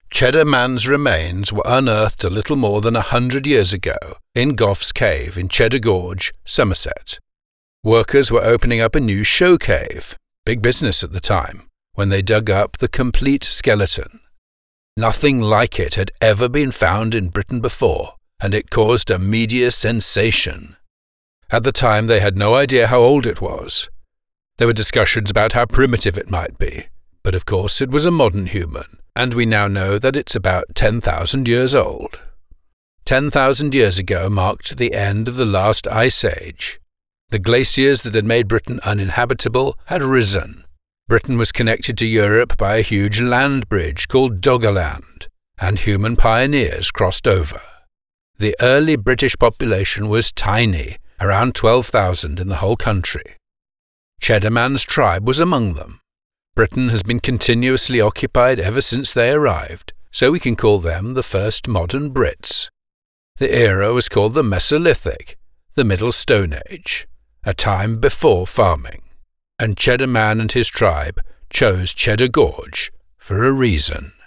British Documentary Narrator:
Museum Narration - Cheddar Man